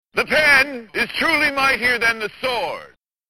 To add a little flavour to the fight, he takes delight in cackling,